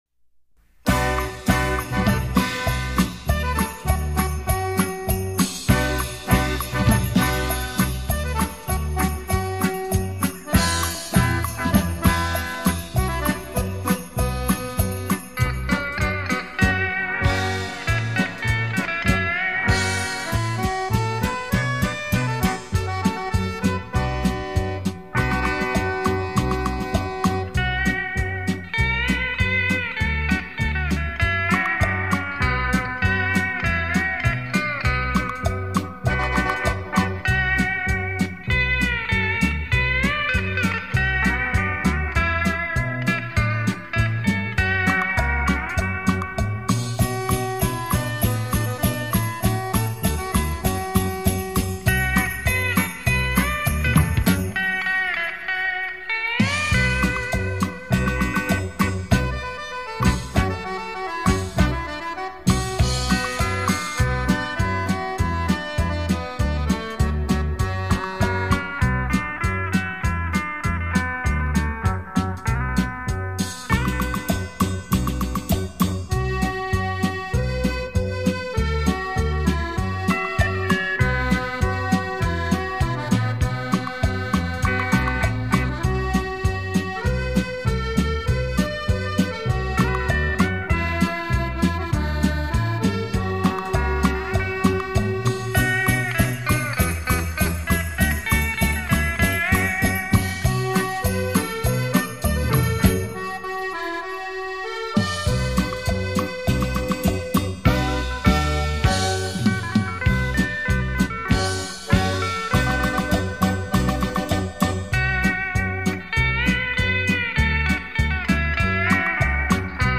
专辑类型：电子琴音乐
超立体现场演奏
发音音量可以自由调节，甚至可以演奏出一个管弦乐队的效果，
另外，电子琴还安装有混响回声，延长音、震音和颤音等
精心打造完美电子音乐，立體效果 環繞身歷聲 超魅力出擊，